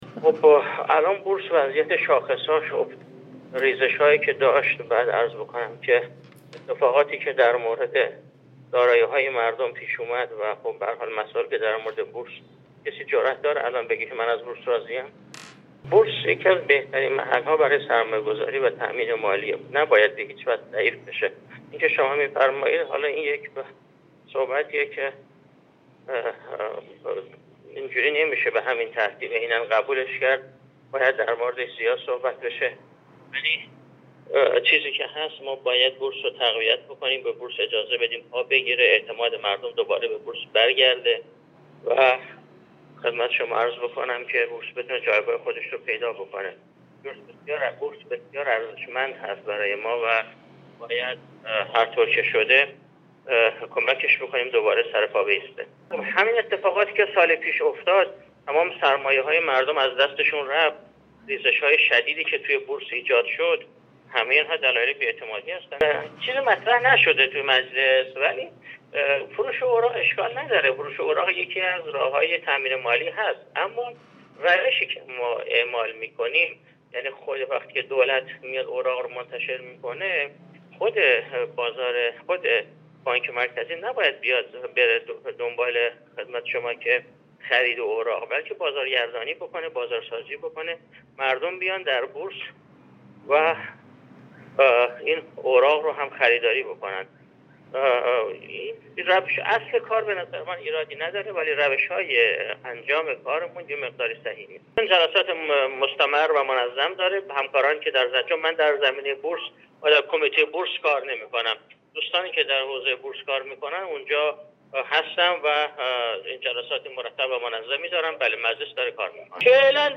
سخنگوی کمیسیون اقتصادی مجلس
غلامرضا مرحبا، سخنگوی کمیسیون اقتصادی در گفت‌وگو با بورس نیوز، با اعلام نارضایتی خود درباره وضعیت بازارسرمایه اظهارکرد: با توجه به افت شاخص و اتفاقات پیش آمده برای دارایی‌های مردم در سال گذشته و مسائلی دیگر در زمینه بورس و بازار سرمایه هیچ گونه رضایتی دیده نمی‌شود.